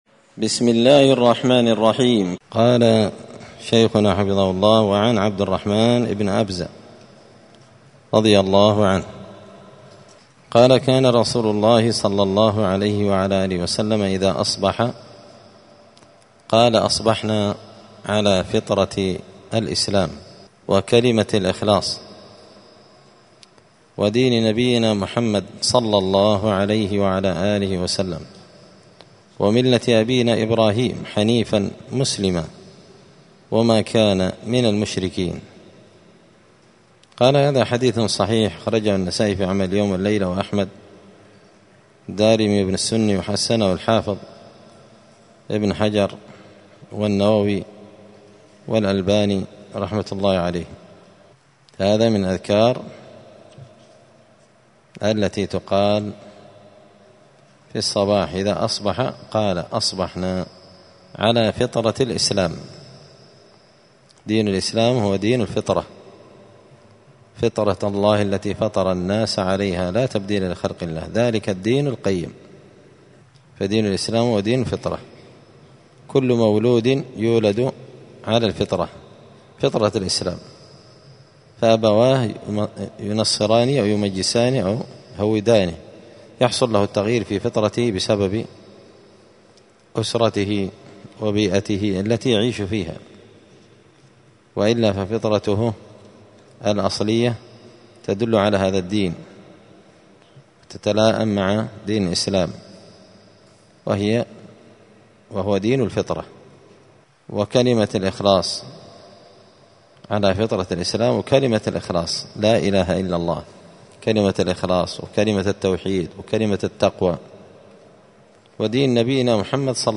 *{الدرس الخامس عشر (15) الحديث التاسع من أذكار الصباح والمساء}*